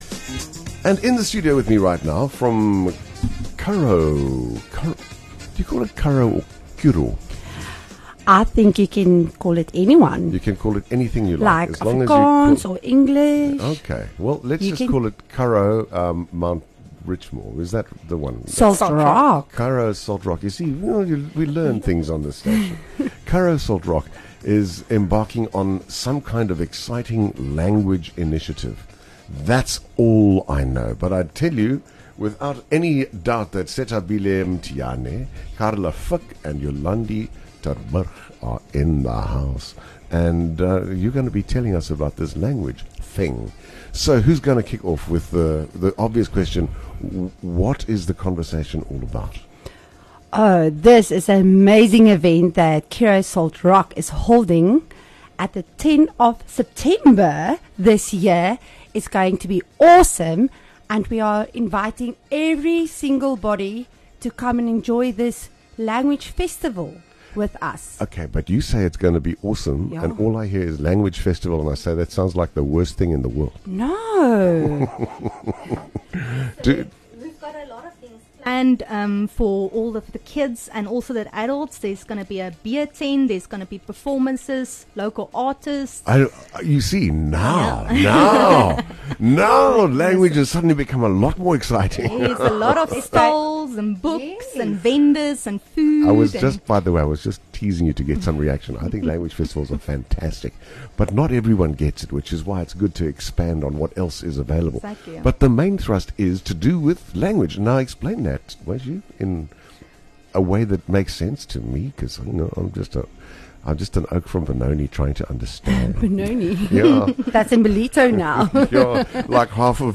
The organisers of the Curro language festival were in studio to discuss what you can expect to discover at the festival on Saturday 10th September.